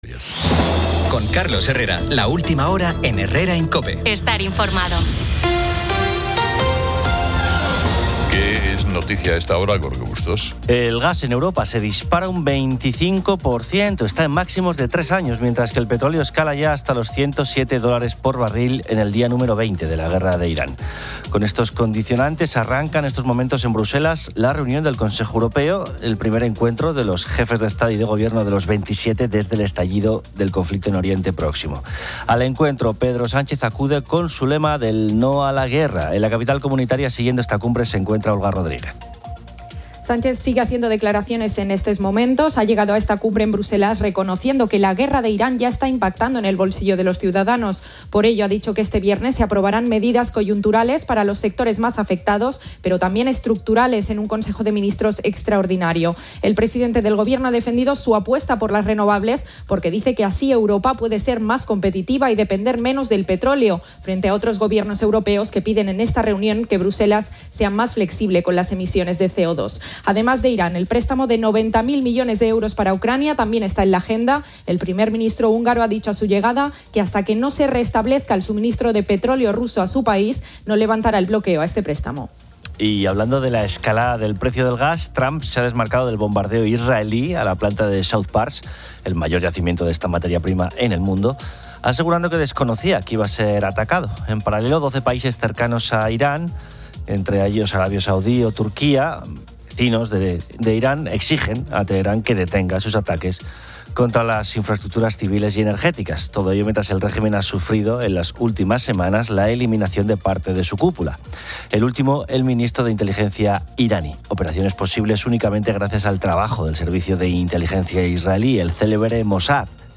El programa dedica un segmento a las “pedidas de mano”, donde oyentes comparten experiencias de propuestas originales y emotivas, como una petición oculta en un libro, en globo sobre la Alhambra o con una sandía.